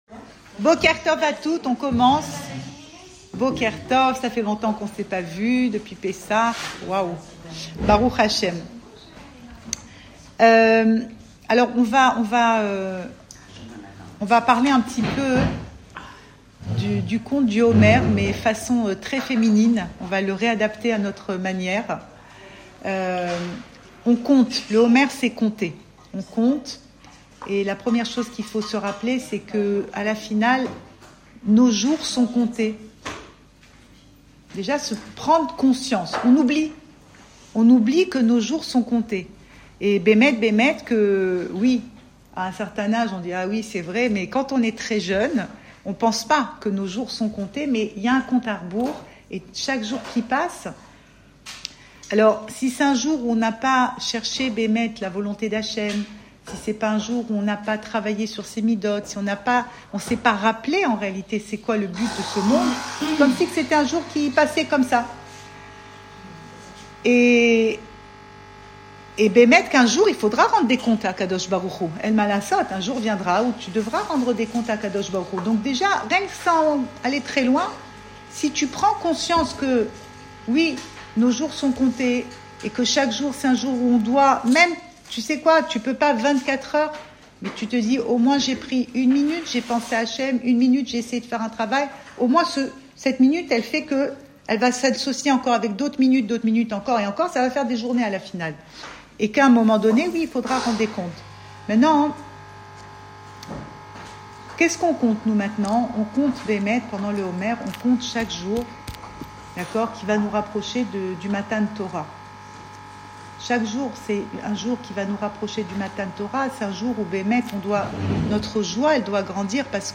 Cours audio Le coin des femmes Le fil de l'info Pensée Breslev - 22 avril 2026 23 avril 2026 Un décompte au féminin. Enregistré à Tel Aviv